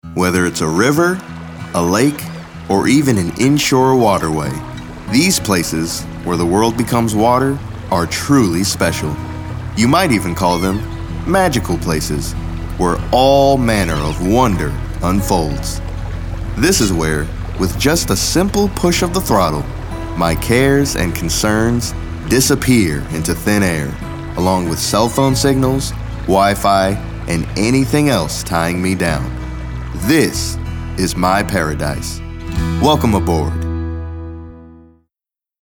anti-announcer, caring, confident, conversational, friendly, genuine, inspirational, millennial, motivational, serious, smooth, thoughtful, warm